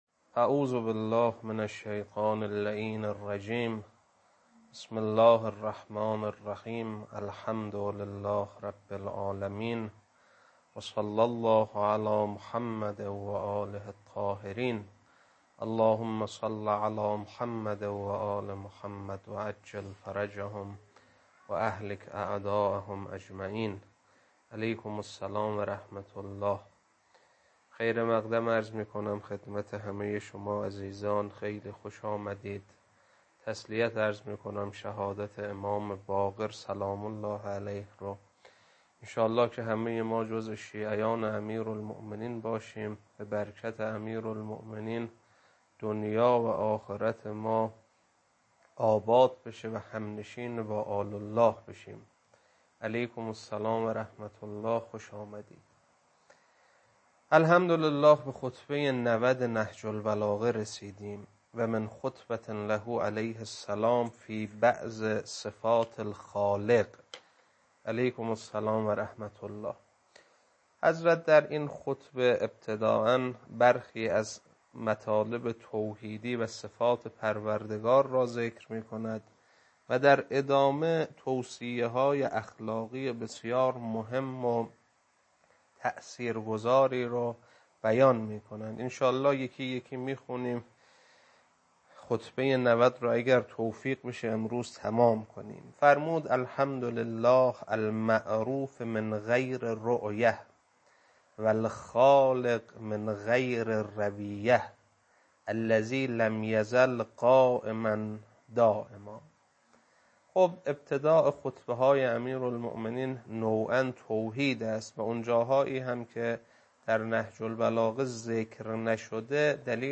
خطبه 90.mp3